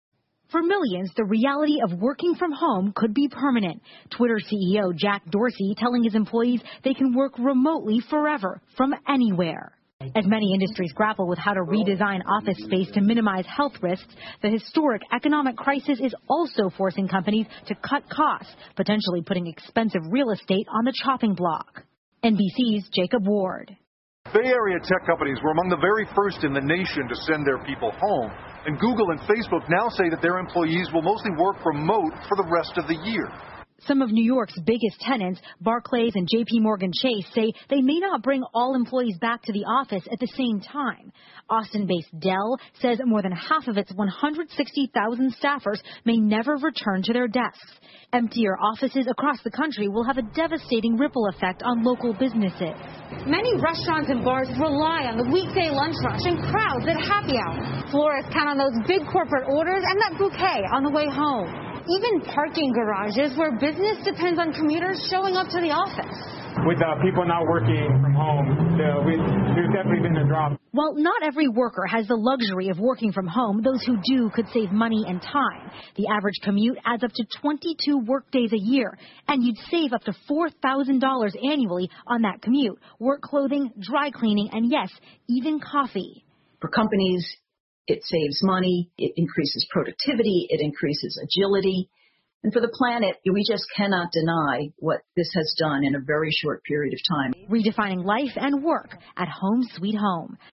NBC晚间新闻 疫情对企业办公影响重大 听力文件下载—在线英语听力室